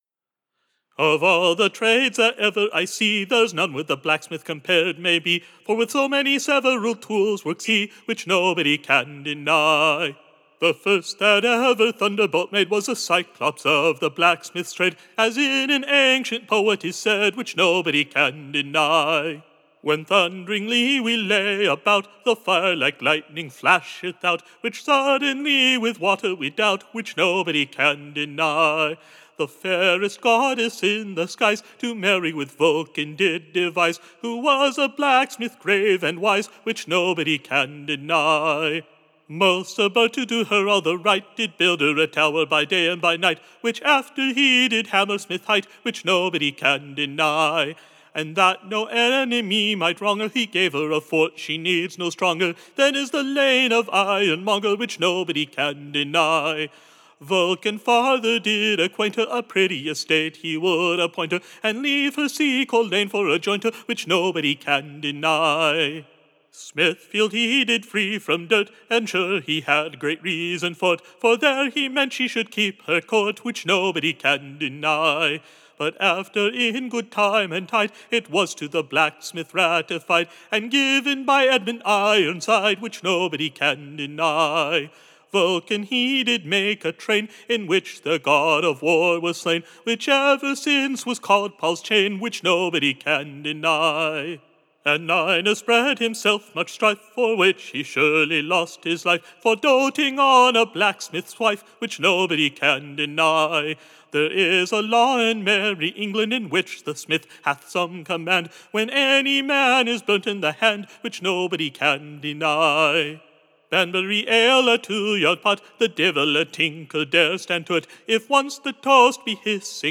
Recording Information Ballad Title A merry new Ballad, both pleasant and sweete, / In praise of the Black-Smith, which is very meete.